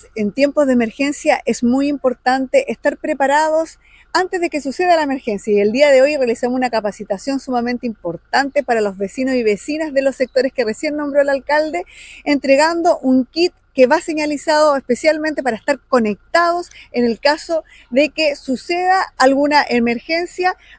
Durante la actividad, la seremi Yenny Valenzuela subrayó la importancia de fortalecer las capacidades locales antes de que ocurra una emergencia “Es muy importante estar preparados antes de que suceda la emergencia y el día de hoy realizamos una capacitación sumamente importante para los vecinos y vecinas del sector costero de la comuna de Huasco, entregando un kit que va señalizado, especialmente, para estar conectados en el caso de que suceda alguna emergencia”, señaló la autoridad.